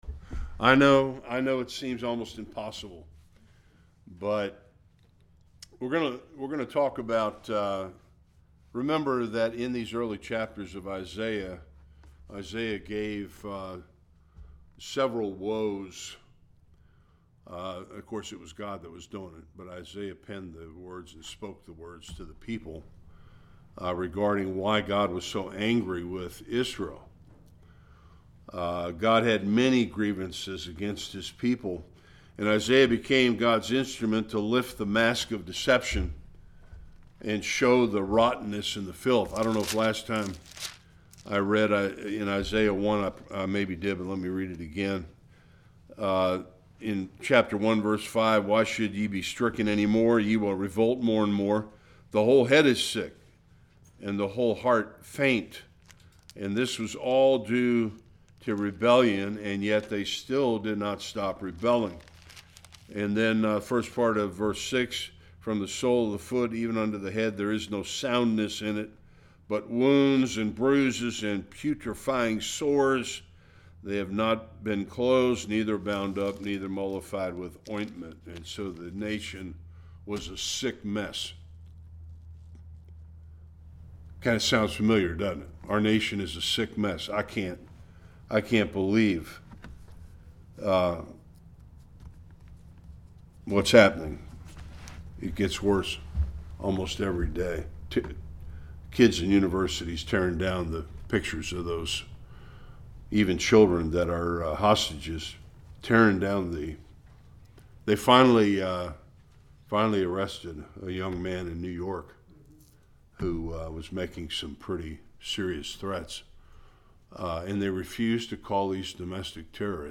Isaiah 5 Service Type: Bible Study God pronounced 5 woes against Israel in Isaiah’s day that tragically could be pronounced against America.